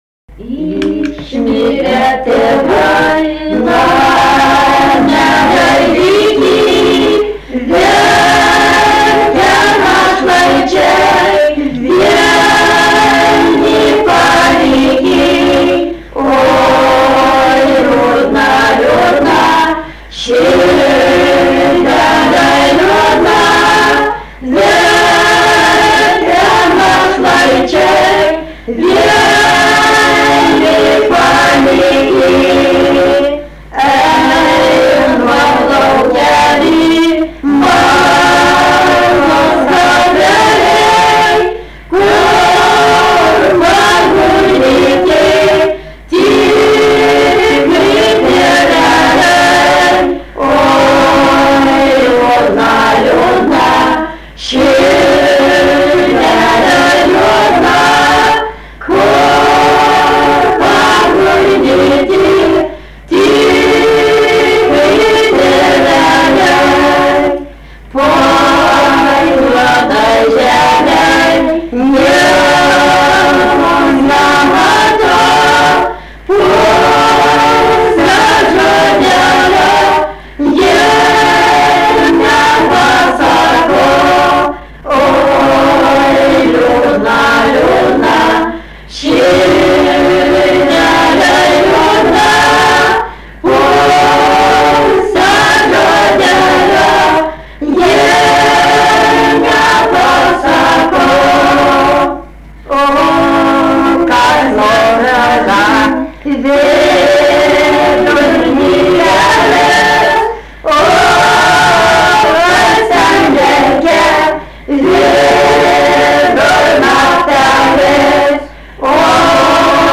Moterų grupė
daina
Medeikiai
vokalinis